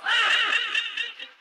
Gattlar_roar.ogg